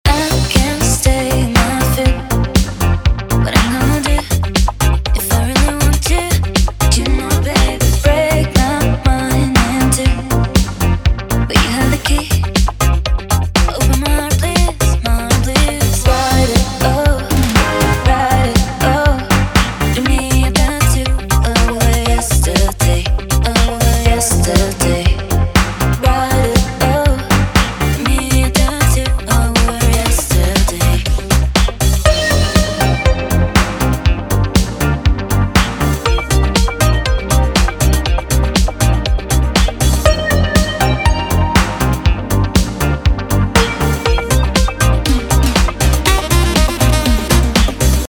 По два такта поочерёдно. Никакой коррекции вообще не применял. Две голые вавки.